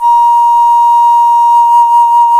Index of /90_sSampleCDs/Roland LCDP04 Orchestral Winds/FLT_Alto Flute/FLT_A.Flt vib 1
FLT ALTO F08.wav